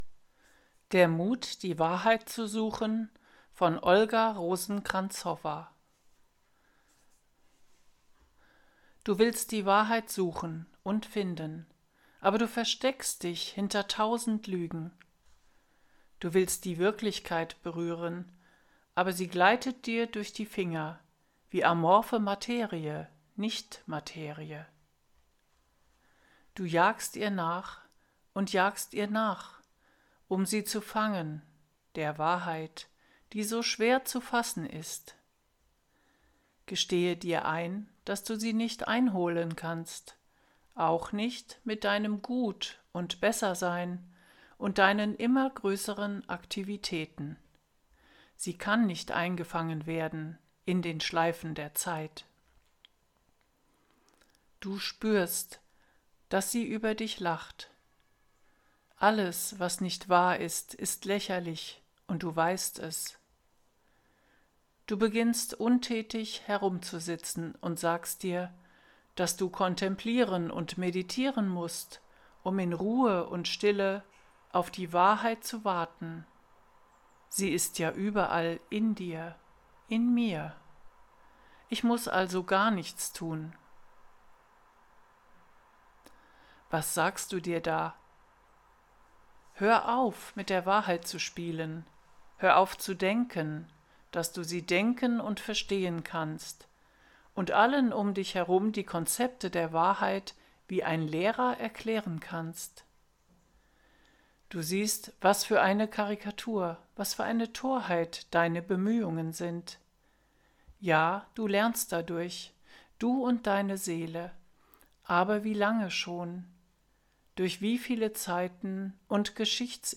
Artikel vorgelesen